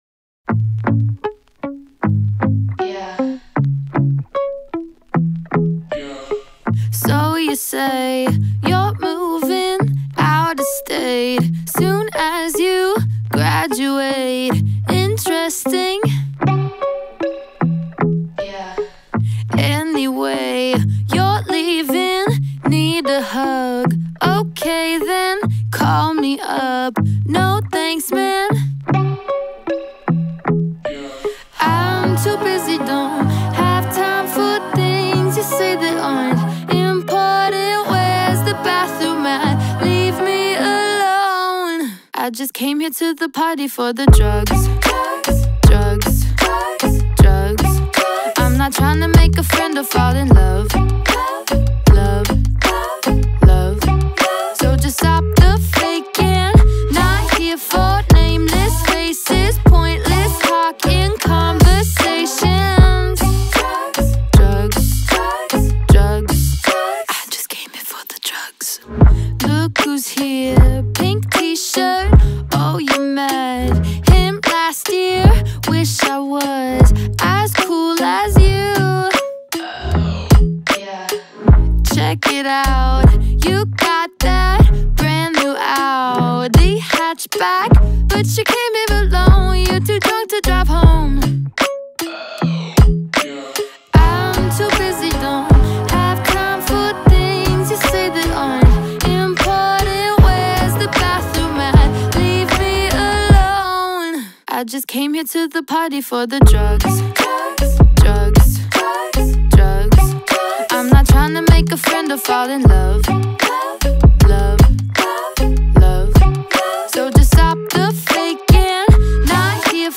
BPM50-172
Audio QualityCut From Video